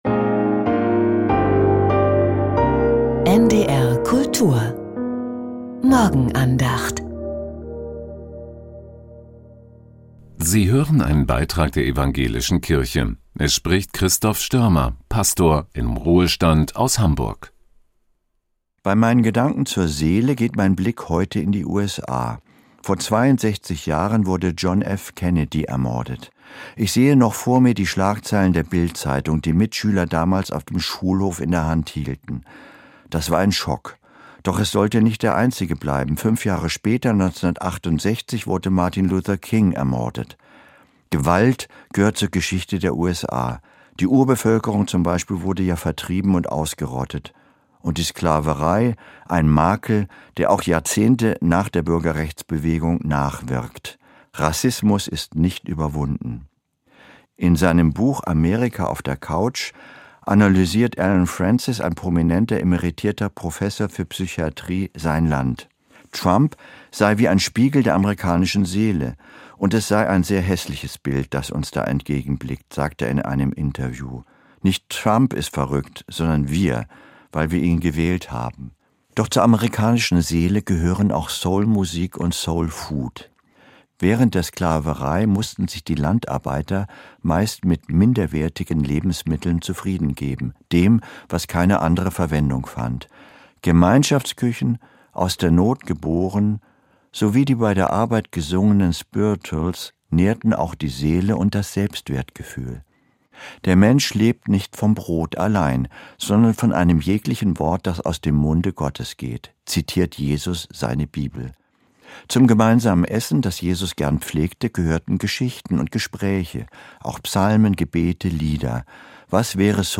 Soulmusik und Soulfood ~ Die Morgenandacht bei NDR Kultur Podcast